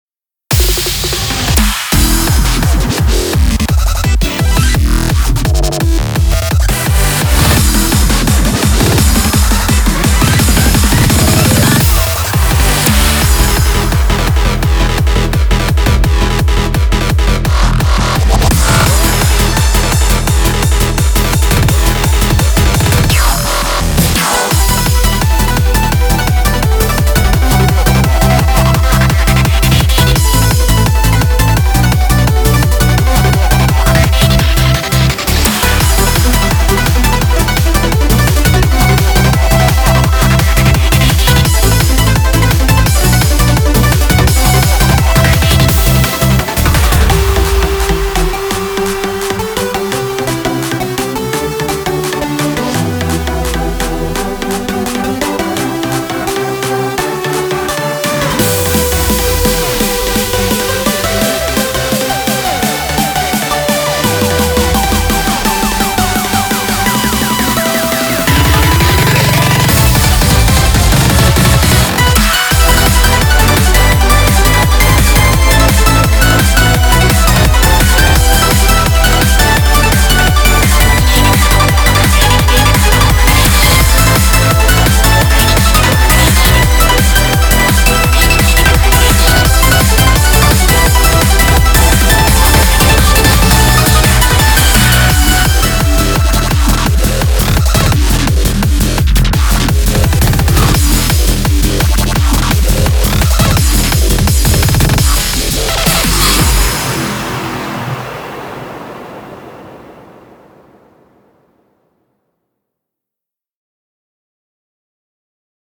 BPM85-170
Audio QualityPerfect (High Quality)